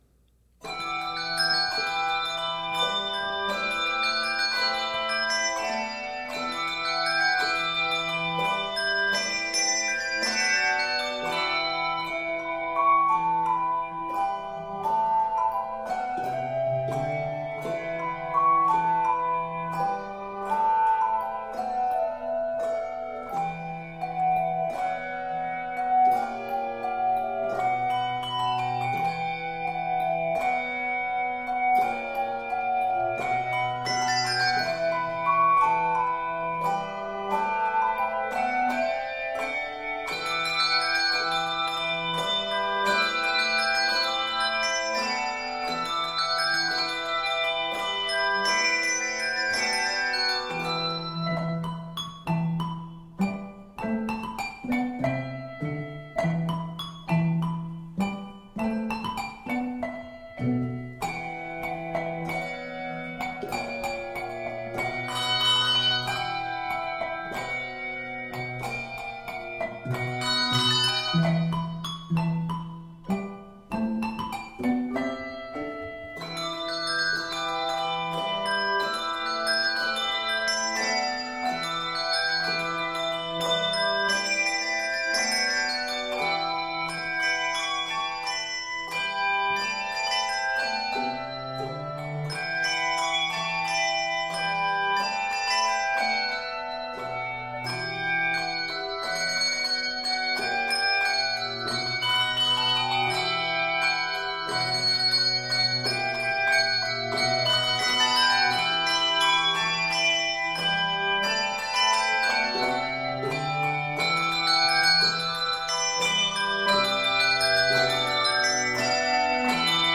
Full of energy and excitement